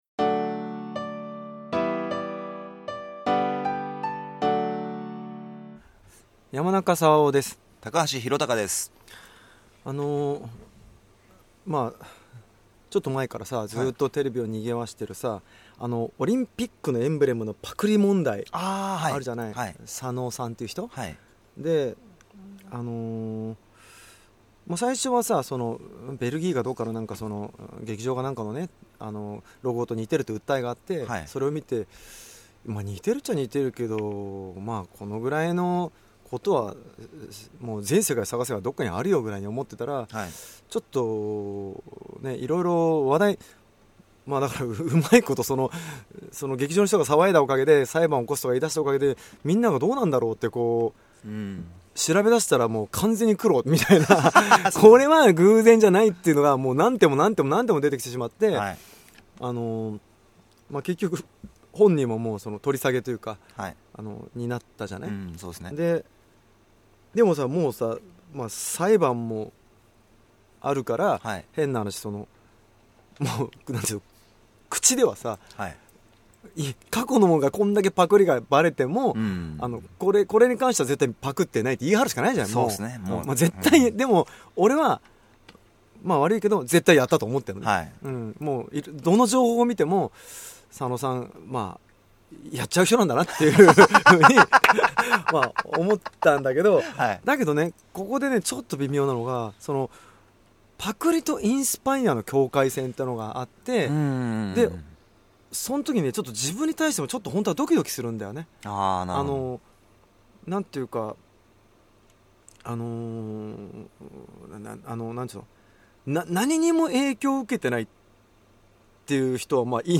Cast：山中さわお Guest：高橋宏貴 (Scars Borough / ELLEGARDEN)